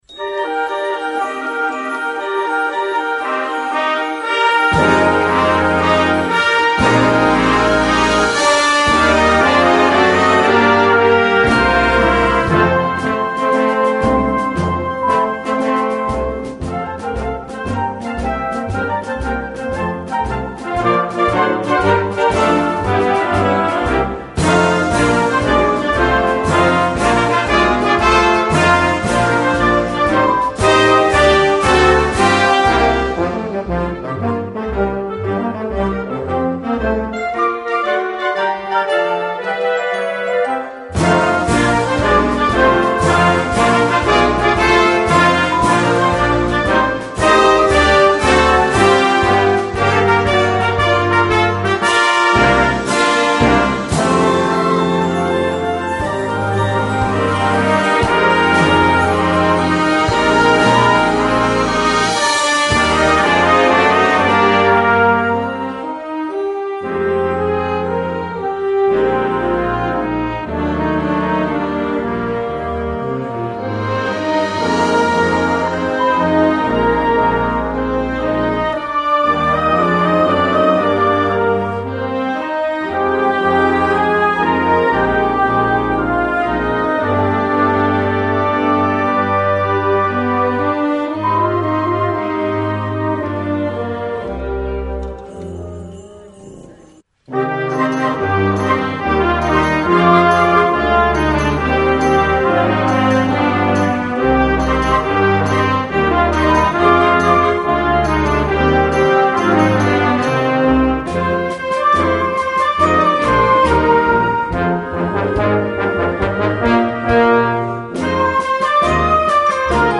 für Jugendblasorchester
Besetzung: Blasorchester